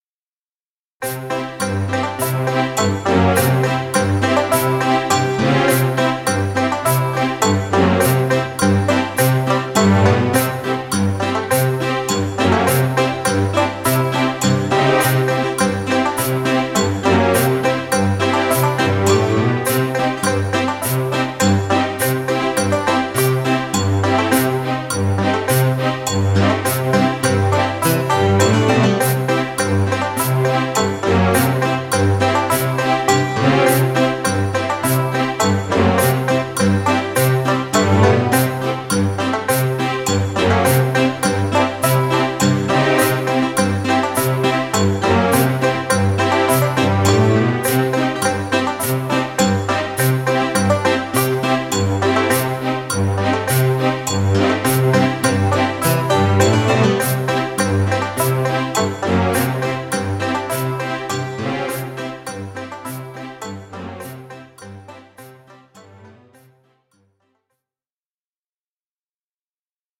recorded from a Roland Sound Canvas SC-55mkII